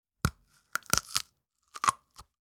На этой странице собраны натуральные аудиоэффекты, связанные с огурцами: от хруста свежего овоща до звуков его выращивания.
Хруст разламывающегося огурца